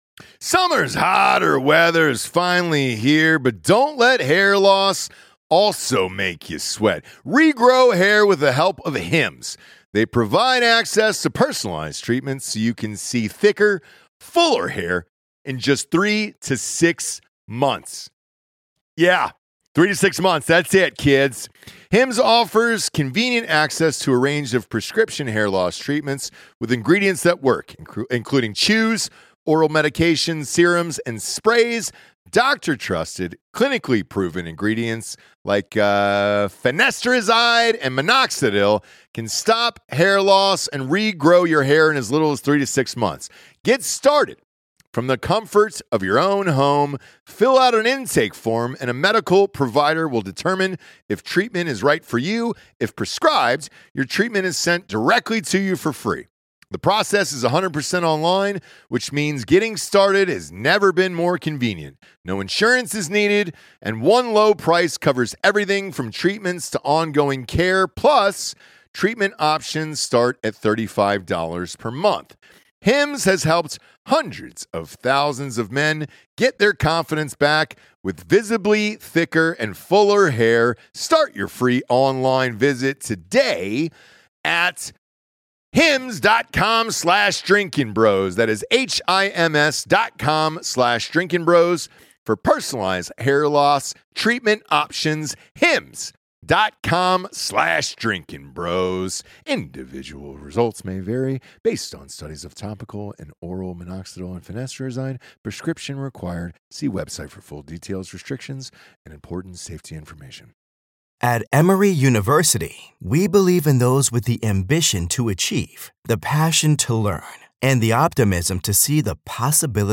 live from the Special Operators Transition Fund Charity Golf Tournament